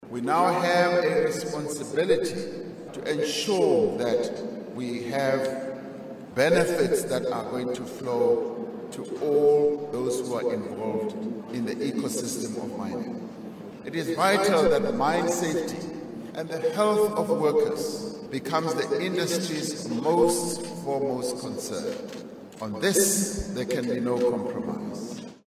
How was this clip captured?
Addressing delegates at the Mining Indaba at the CTICC, he said it is important that mining companies not only invest in their infrastructure and operations but also in the development and well-being of their employees.